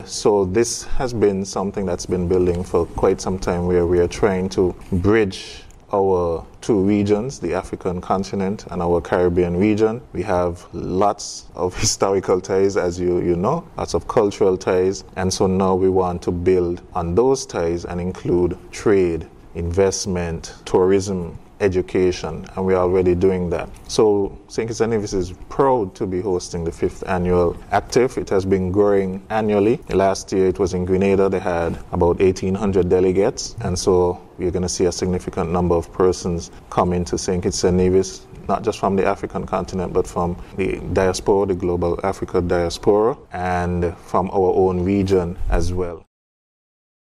Cabinet Secretary, Dr. Marcus L. Natta stated: